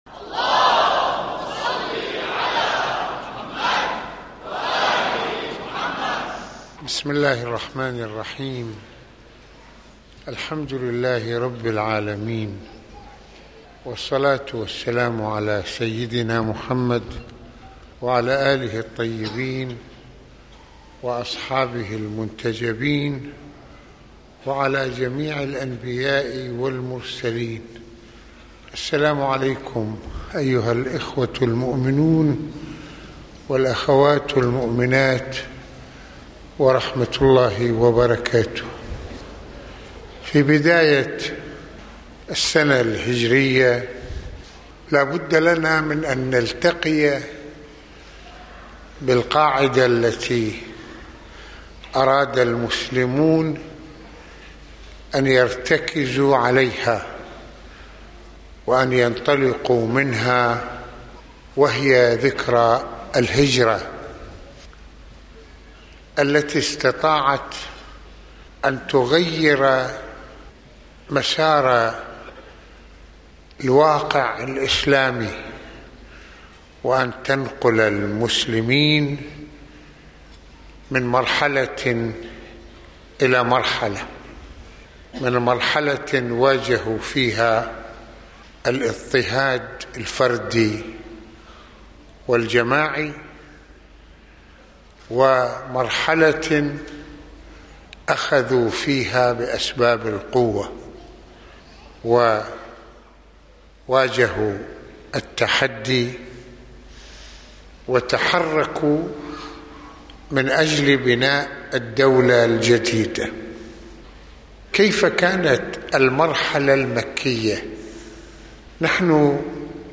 المناسبة : عاشوراء المكان : مسجد الإمامين الحسنين (ع)